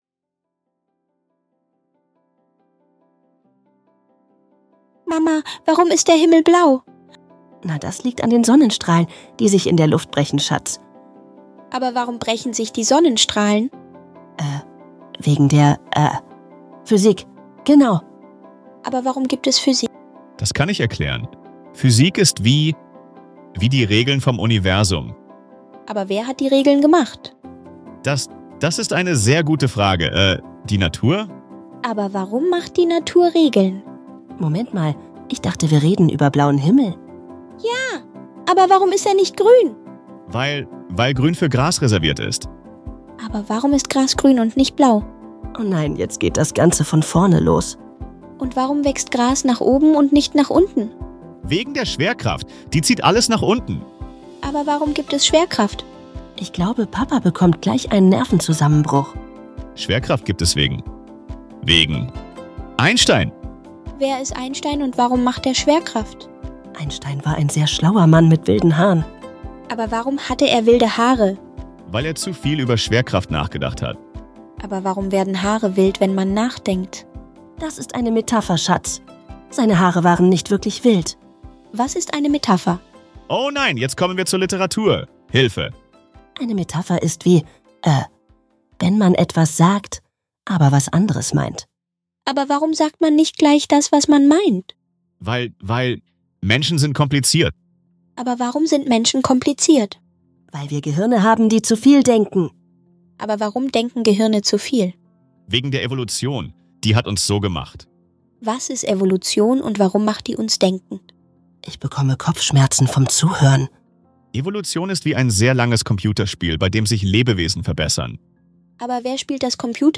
diesem lustigen Dialog erleben wir, wie eine harmlose Frage eine